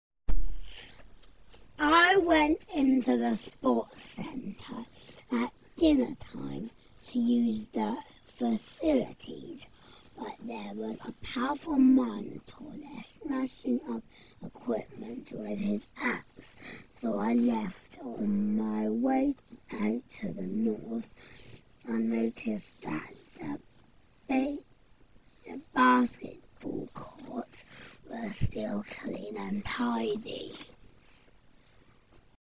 Listen to the sporty guy
sport-centre-sporty-guest.mp3